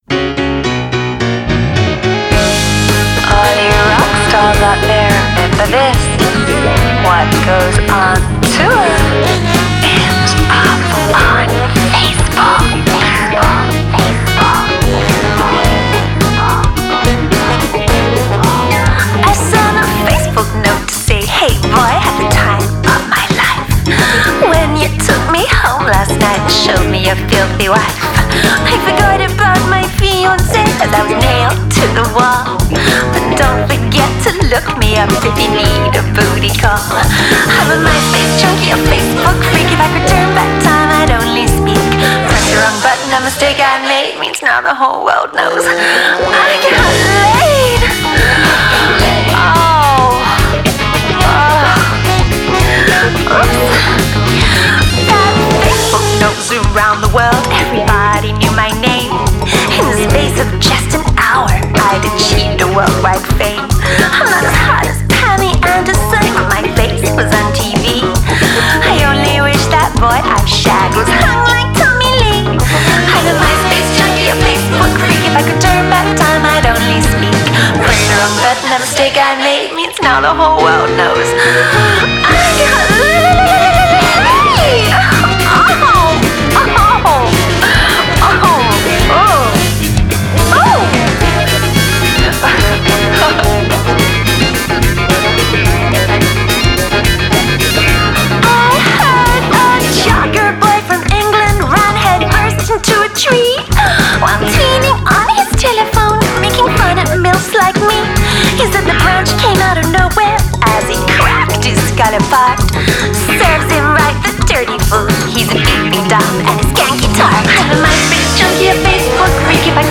piano and backing vocals
guitar
drums